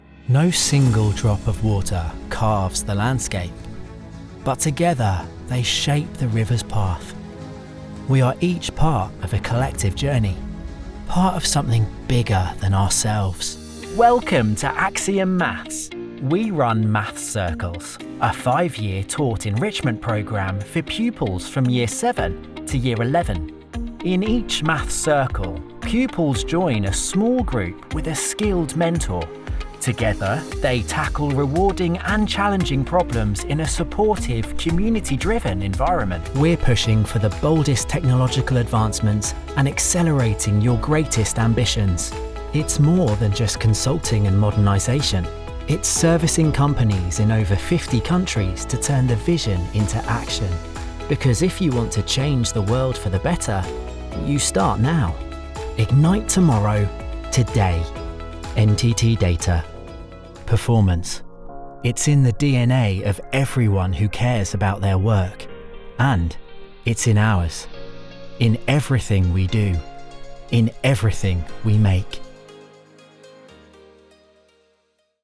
English (British)
Narration
Explainer Videos